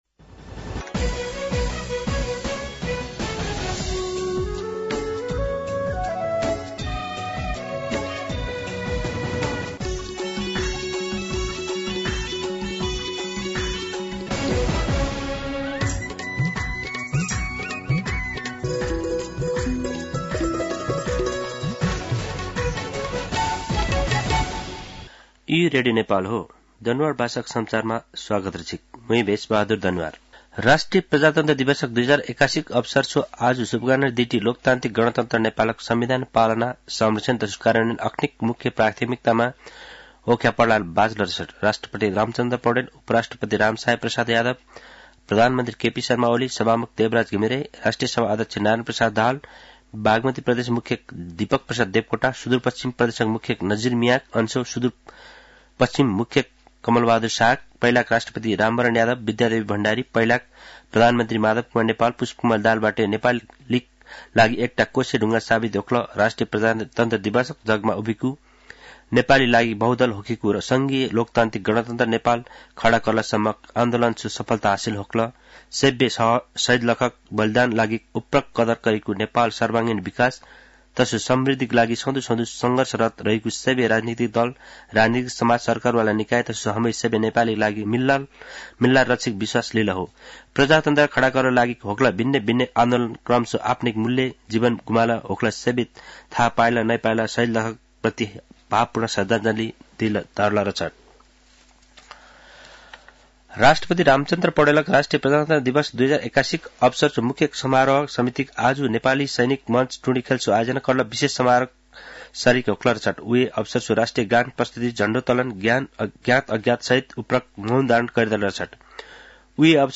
An online outlet of Nepal's national radio broadcaster
दनुवार भाषामा समाचार : ८ फागुन , २०८१
Danuwar-News-07.mp3